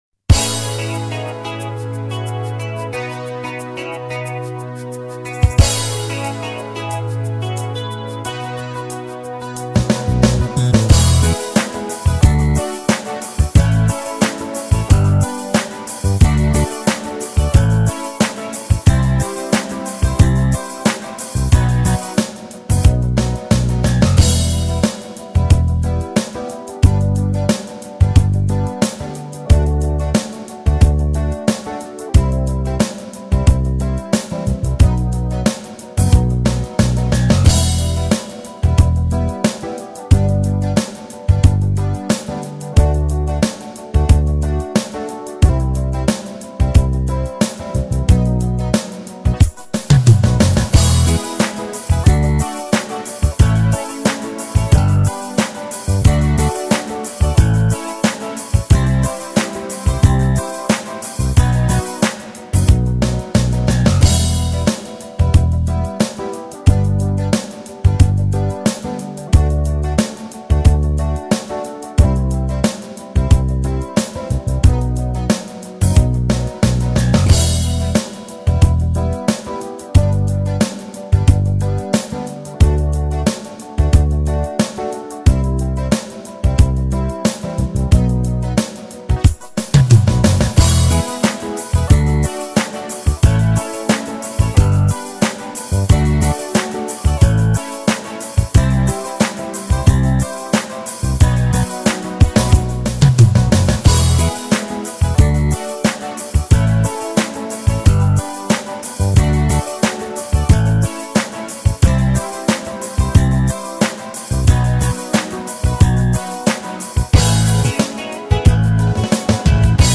Wahlkampfsongs
NeueZeitenKaraoke.mp3